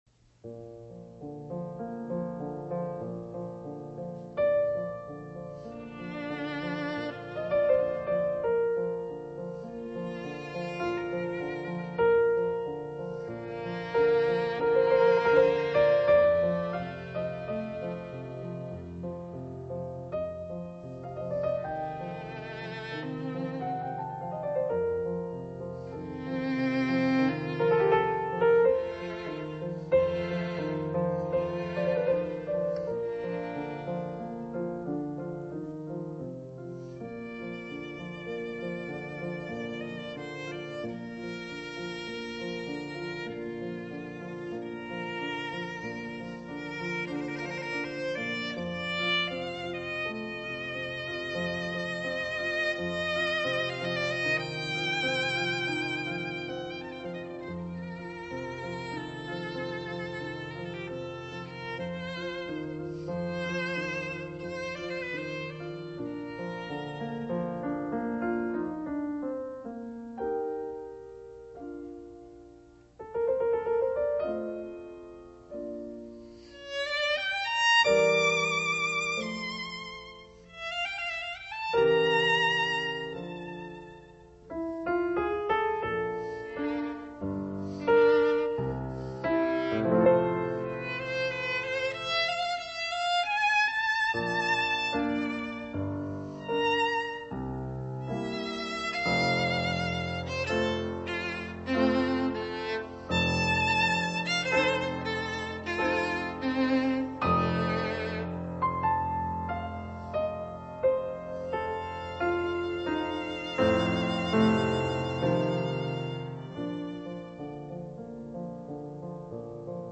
两大巨匠的对话无比的浪漫、多情
你现在听到的是第一乐章快板